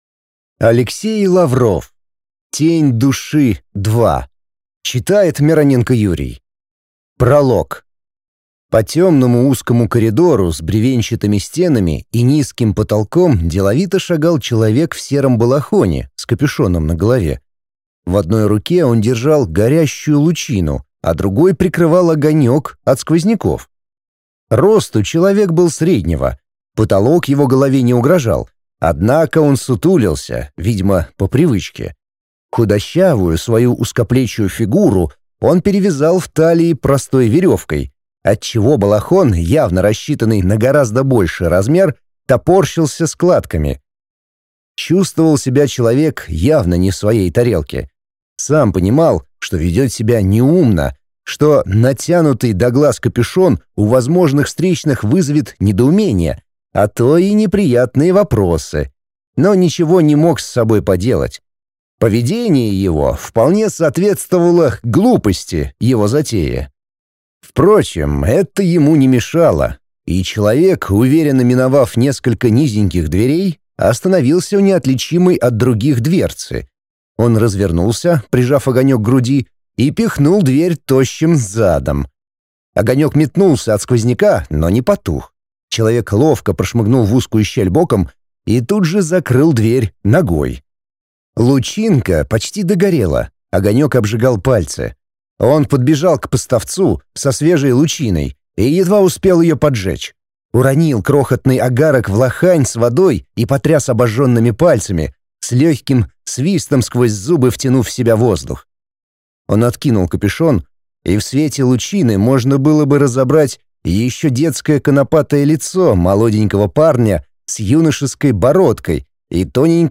Аудиокнига Тень души 2 | Библиотека аудиокниг